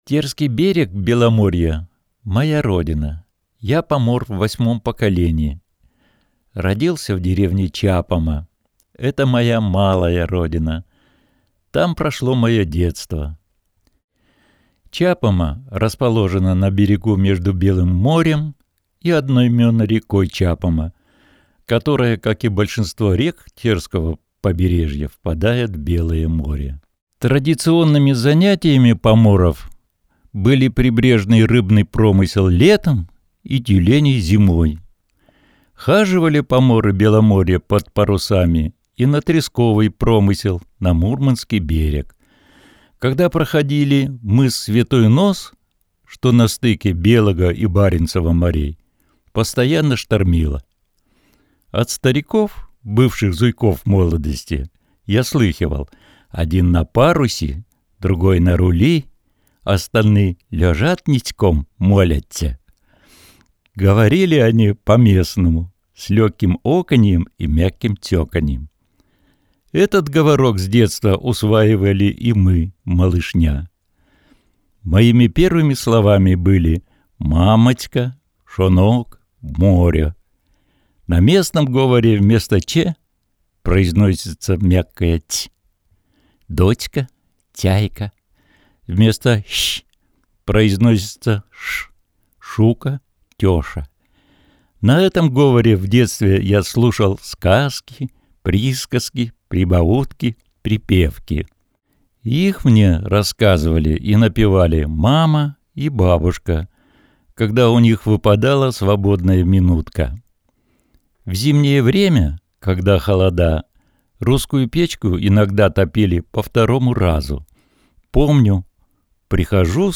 Сурядов В. А. Сказки Беломорья: на родном говоре